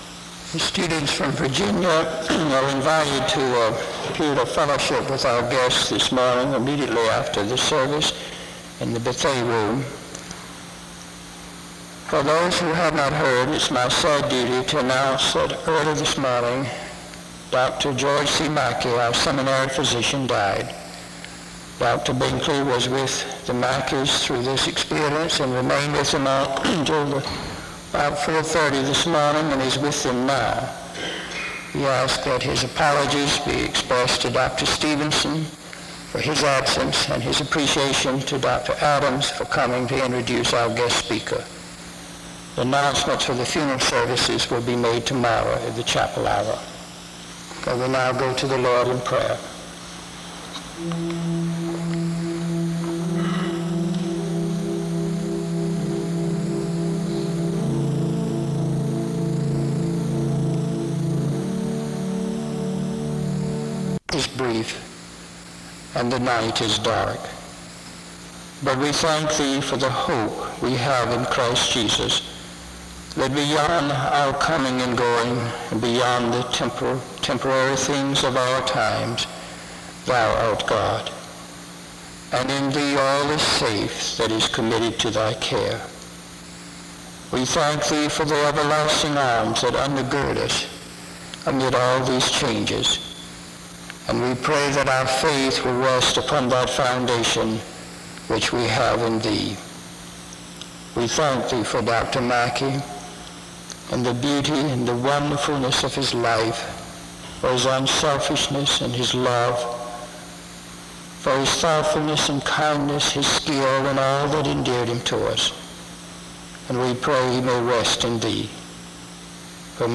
The service begins with an announcement from 0:00-0:50. A time of prayer takes place from 0:52-2:47. The speaker is introduced from 2:57-5:37.